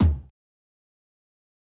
Percs / Sinking Tom